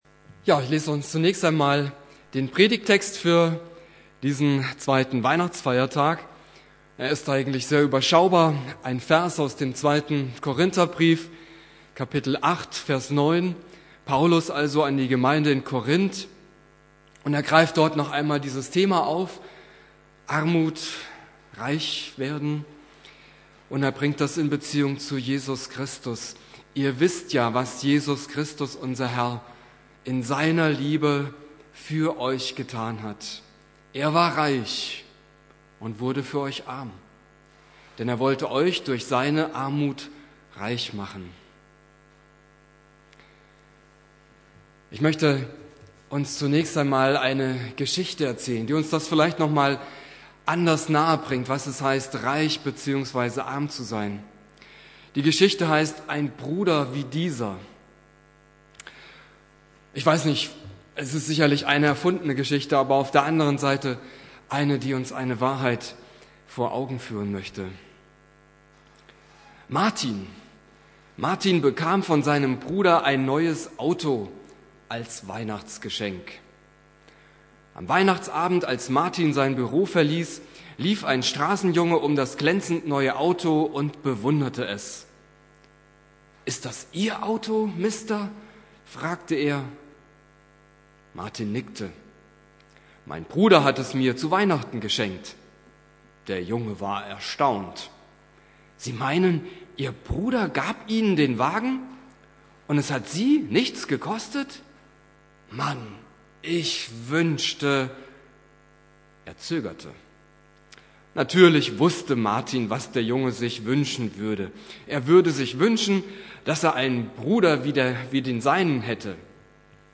2.Weihnachtstag Prediger